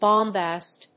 音節bom・bast 発音記号・読み方
/bάmbæst(米国英語), bˈɔmbæst(英国英語)/